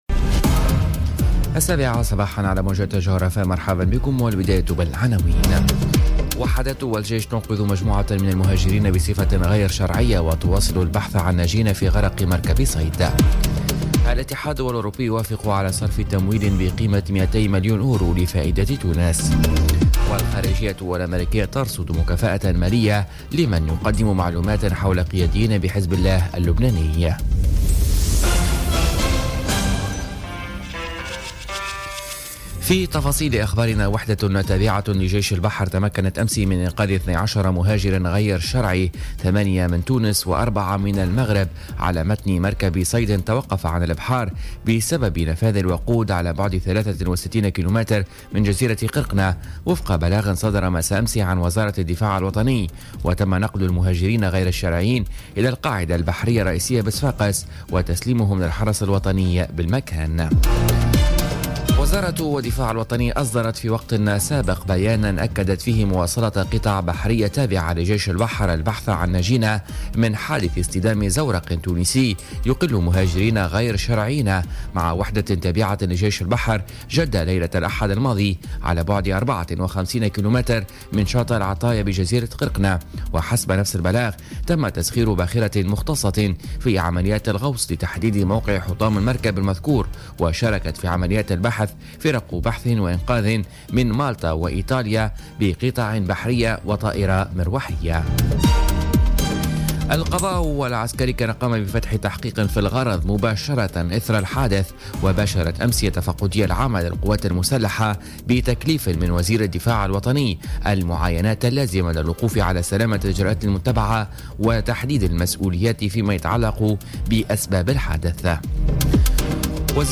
نشرة أخبار السابعة صباحا ليوم الإربعاء 11 أكتوبر 2017